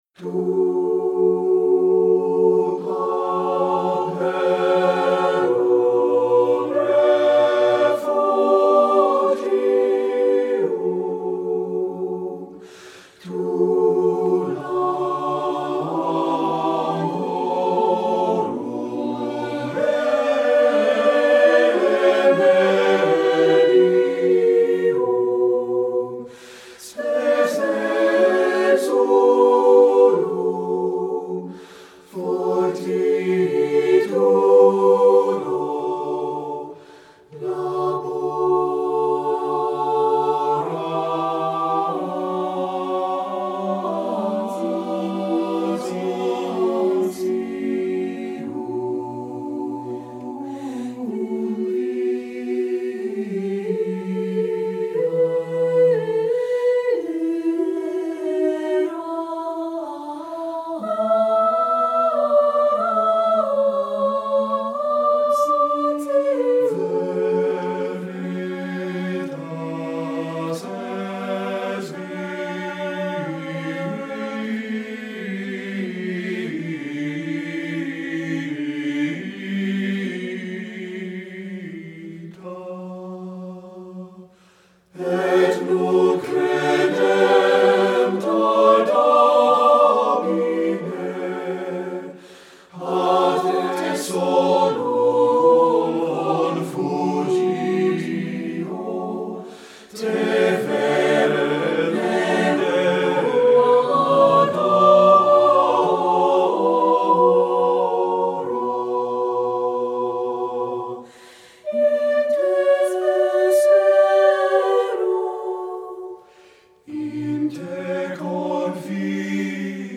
Composer: Spiritual
Voicing: SSAATTBB